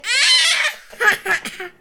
Voices - young child - screech, chuckle
american baby child chuckle coo cute english female sound effect free sound royalty free Voices